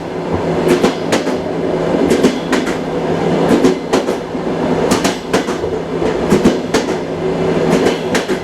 new_rus_train_sound2.ogg